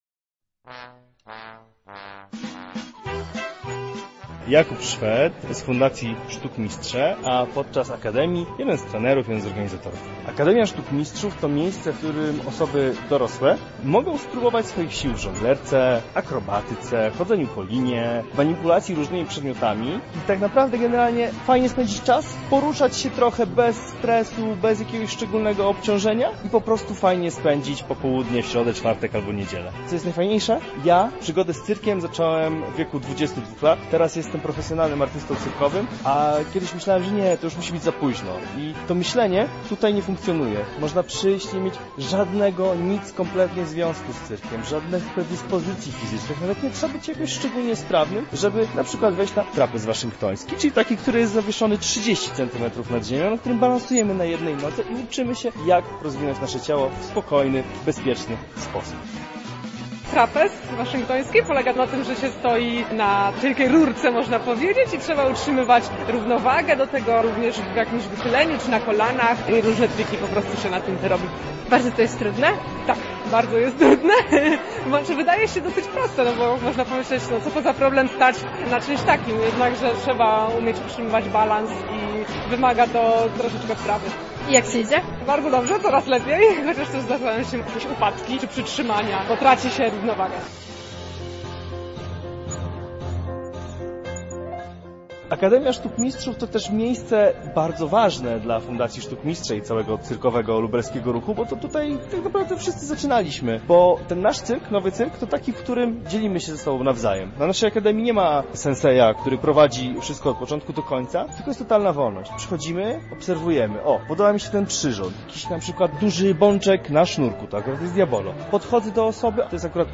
Nasza reporterka sprawdziła jakie dyscypliny sztuki nowego cyrku szczególnie przypadły uczestnikom do gustu: